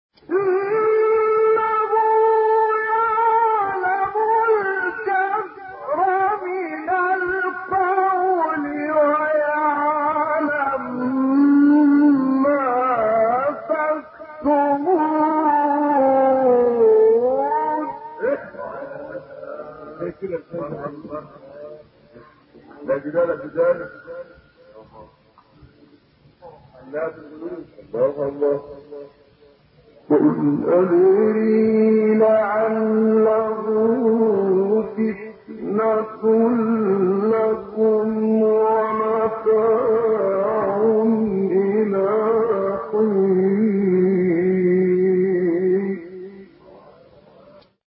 گروه فعالیت‌های قرآنی: فرازهای صوتی دلنشین با صوت قاریان برجسته مصری ارائه می‌شود.
مقطعی فنی از محمد عبدالعزیز حصان